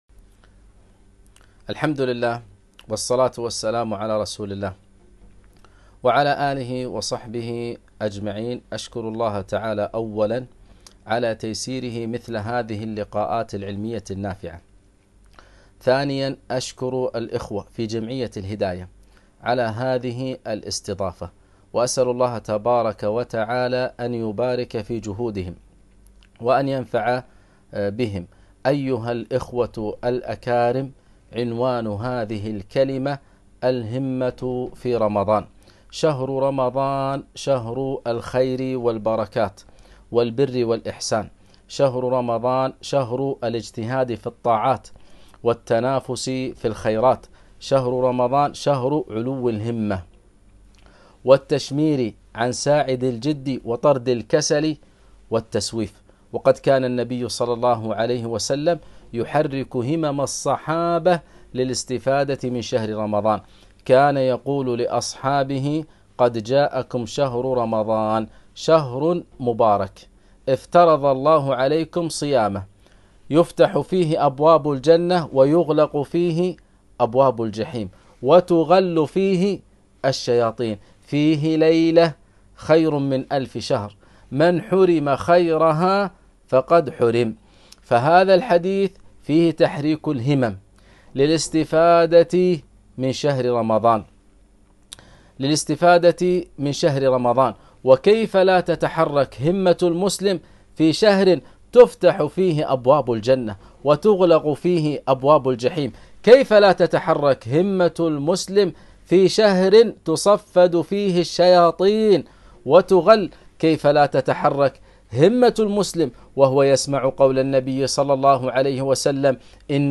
محاضرة - الهمة في رمضان